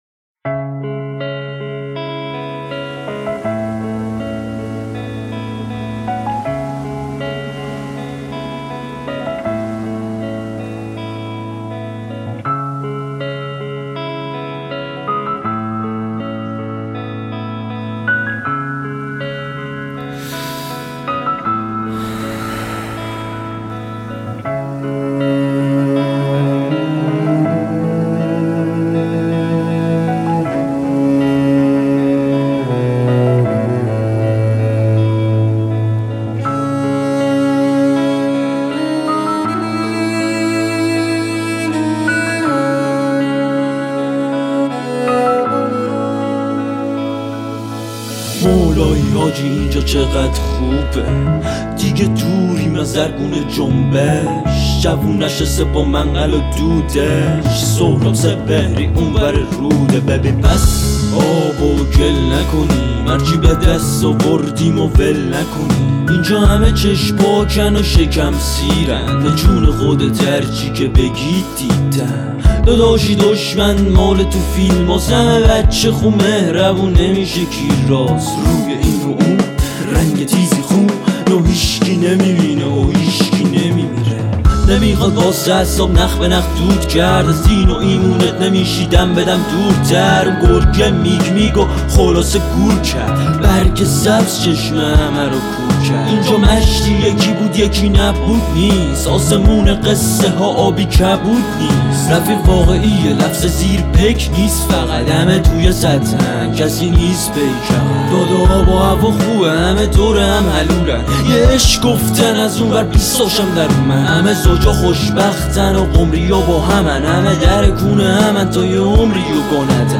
انگار تو صداش مورفین داره.